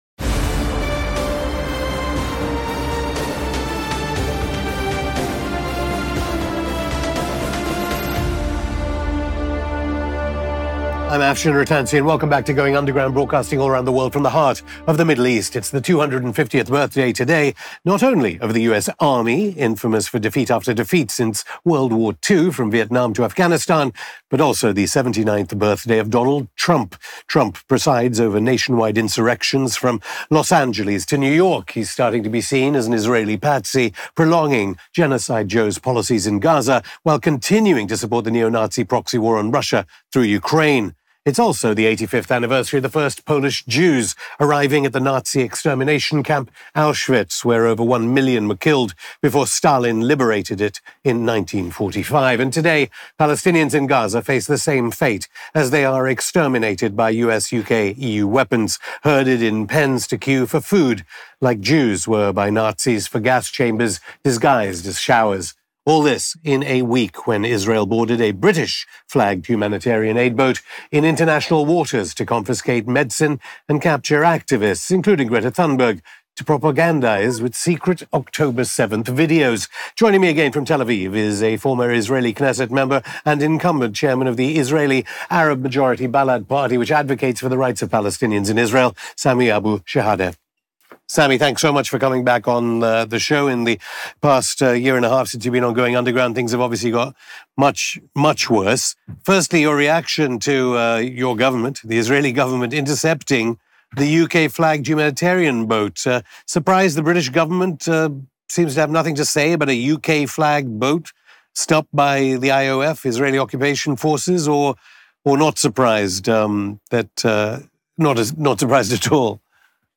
Hosted by Afshin Rattansi